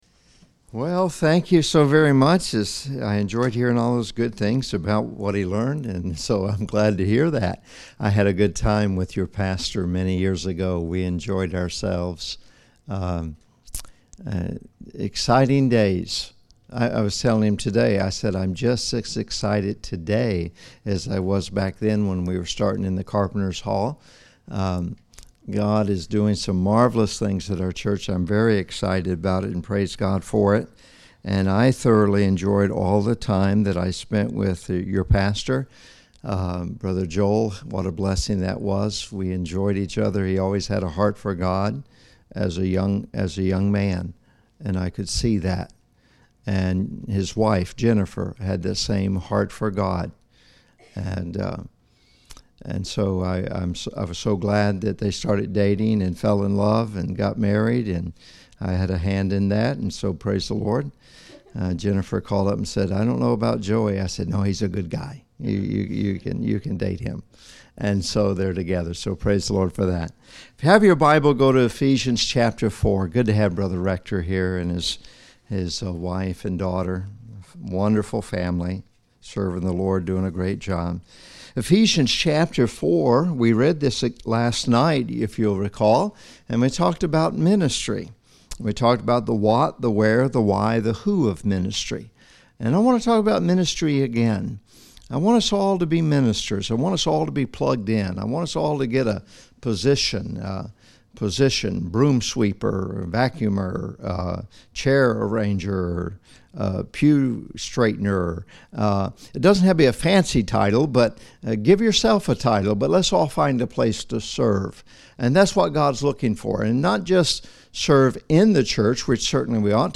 ODBC Audio Sermons
Guest Preachers